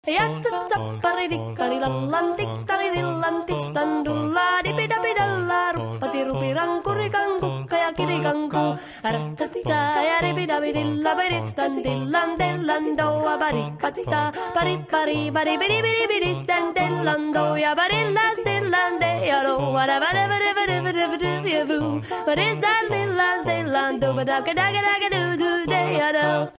саундтрек к мульту.